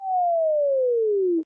arc_reduce.ogg